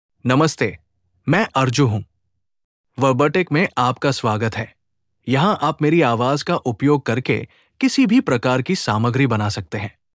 Arjun — Male Hindi (India) AI Voice | TTS, Voice Cloning & Video | Verbatik AI
ArjunMale Hindi AI voice
Arjun is a male AI voice for Hindi (India).
Voice sample
Listen to Arjun's male Hindi voice.
Arjun delivers clear pronunciation with authentic India Hindi intonation, making your content sound professionally produced.